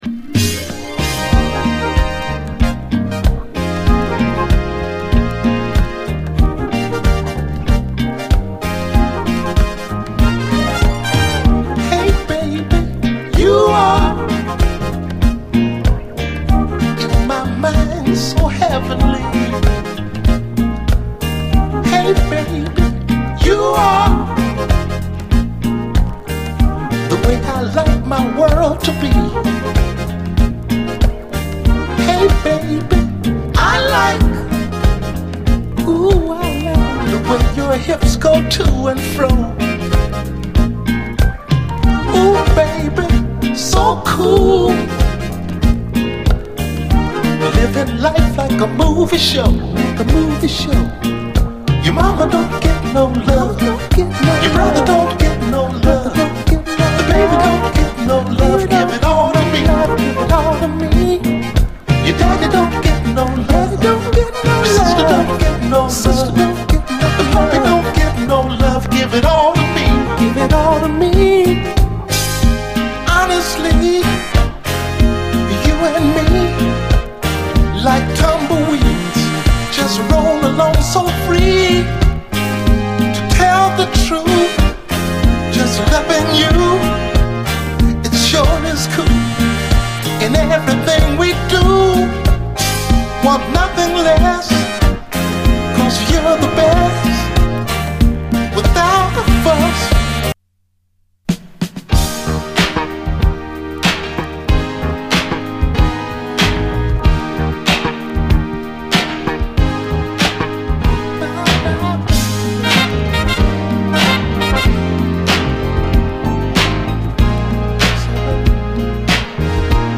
SOUL, 70's～ SOUL
程よくシンセを取り入れたメロウ・ソウル
アコースティックなサウンドに程よくシンセを取り入れたメロウ・ソウル